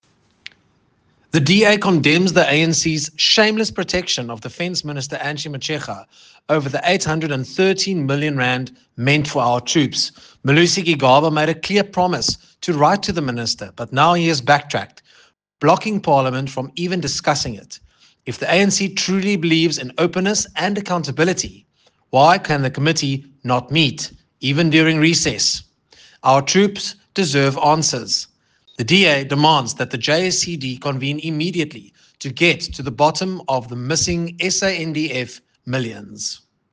Afrikaans soundbite by Nicholas Gotsell MP.